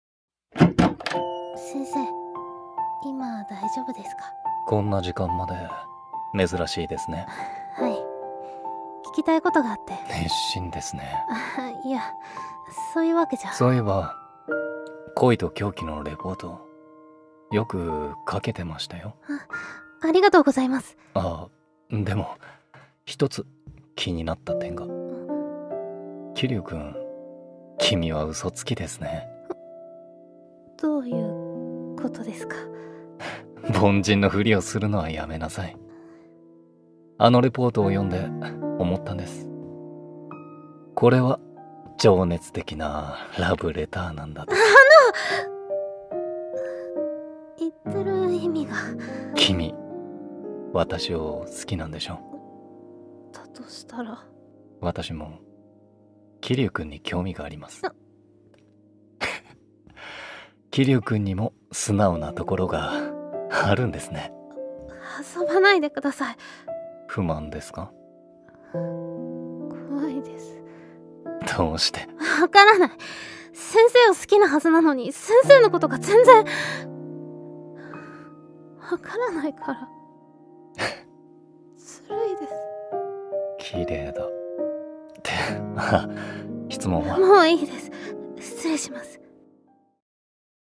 【声劇】恋と狂気